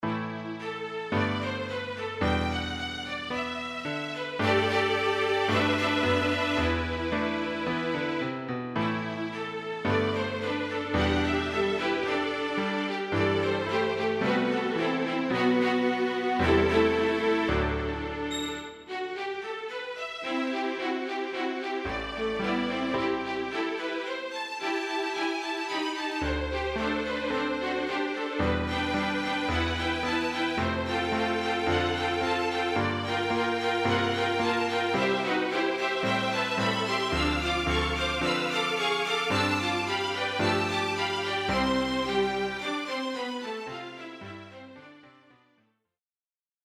3 Violinen und Klavier